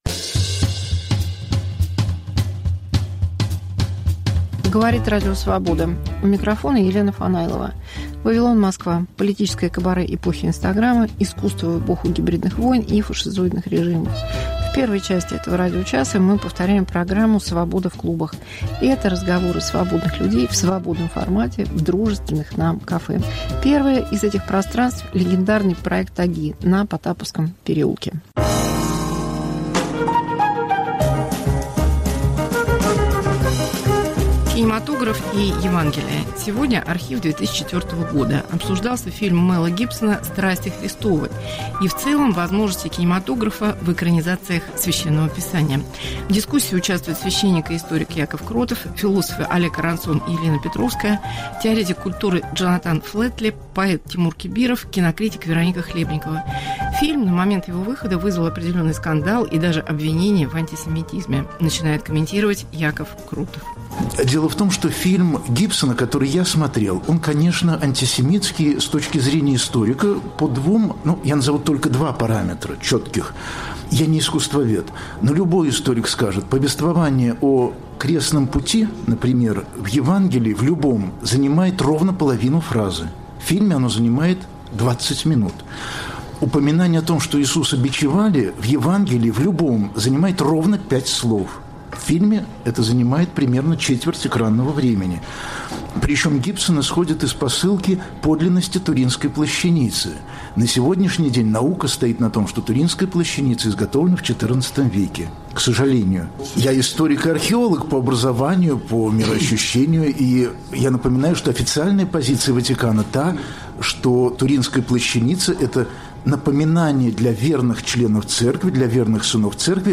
Мегаполис Москва как Радио Вавилон: современный звук, неожиданные сюжеты, разные голоса. 1. Дискуссия о фильме Мэла Гибсона "Страсти Христовы" из архива 2004. 2.